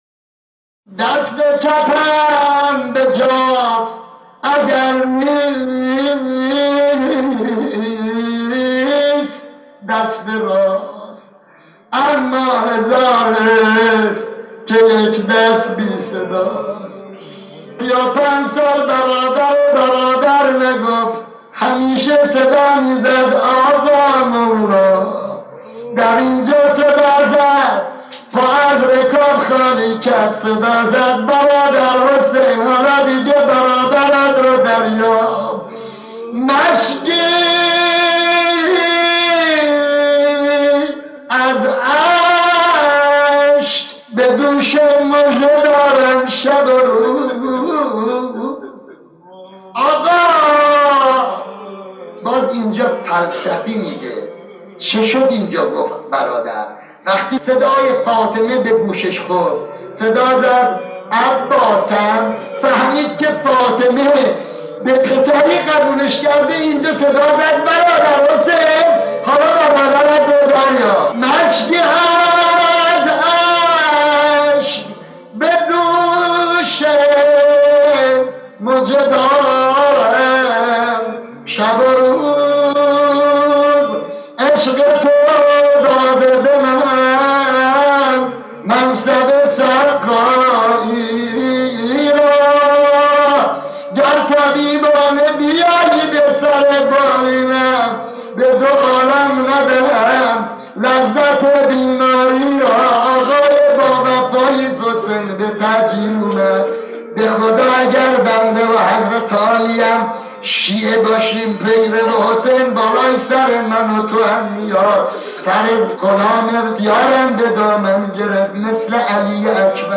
نوای ماندگار مداحان دیروز/ ۹